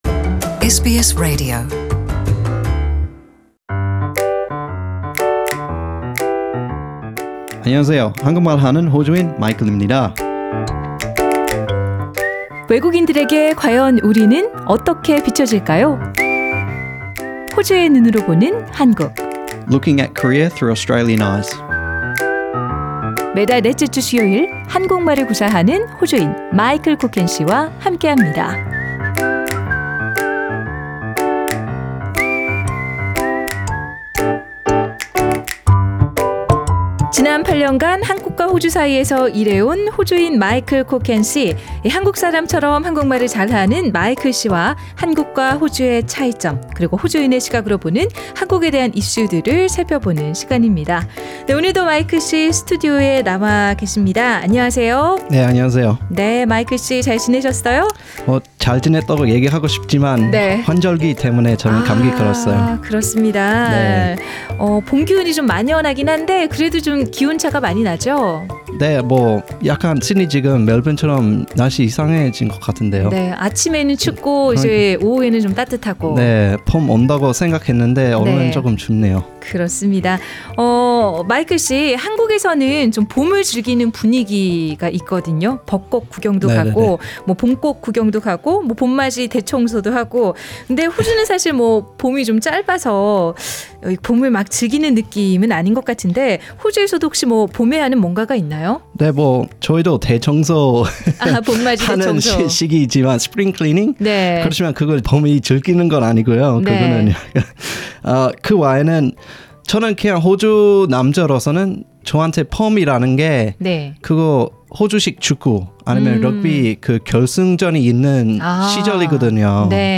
상단의 팟 캐스트를 통해 전체 인터뷰를 들으실 수 있습니다.